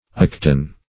aketon - definition of aketon - synonyms, pronunciation, spelling from Free Dictionary
aketon - definition of aketon - synonyms, pronunciation, spelling from Free Dictionary Search Result for " aketon" : The Collaborative International Dictionary of English v.0.48: Aketon \Ak"e*ton\, n. [Obs.]